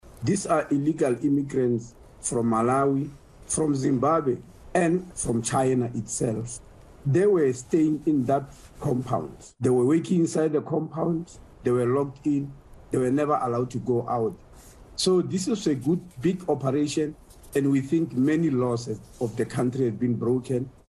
He spoke to eNCA and says the victims were rescued after a tip-off from members of the public.